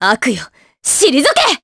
Aselica-Vox_Skill1_jp.wav